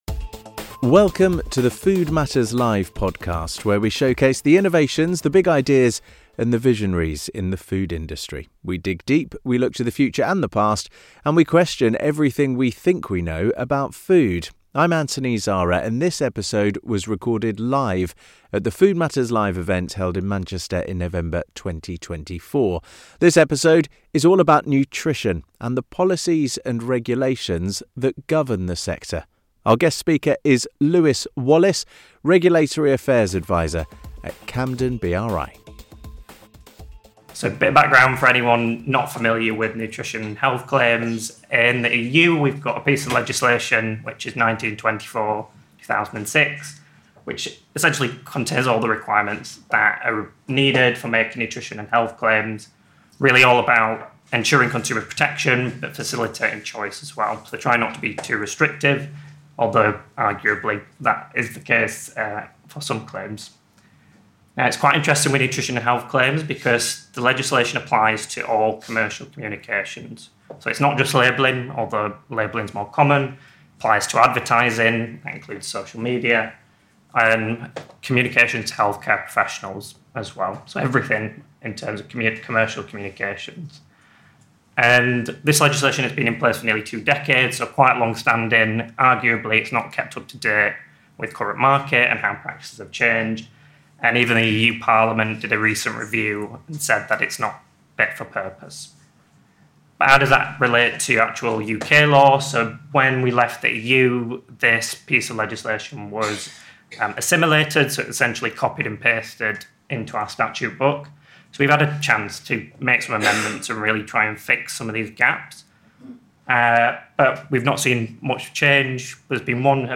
In this episode of the Food Matters Live podcast, recorded at our event in Manchester in November 2024, we tackle a deceptively simple question: Who's actually watching what food companies say about nutrition, and are the current rules fit for purpose in our digital age? Our guest speaker unpacks the world of nutrition regulations, from the glaring gaps in current legislation to the wild west of social media marketing.